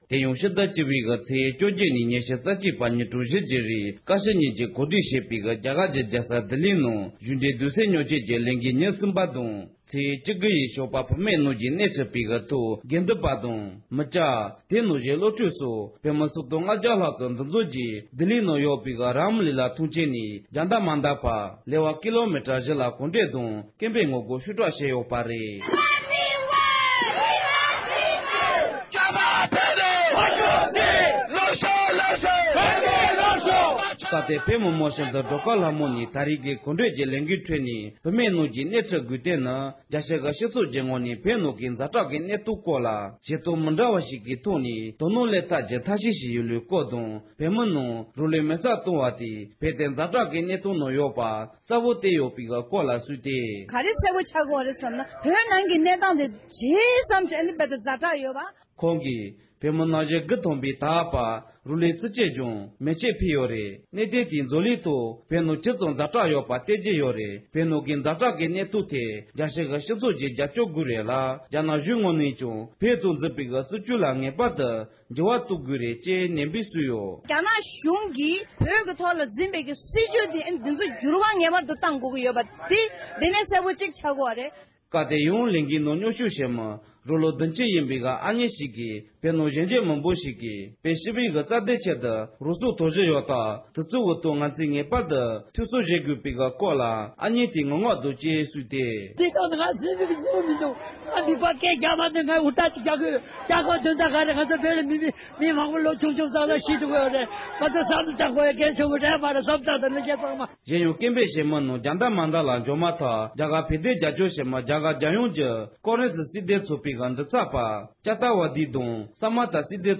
ས་གནས་ས་ཐོག་ནས་བཏང་འབྱོར་བྱུང་བའི་གནས་ཚུལ་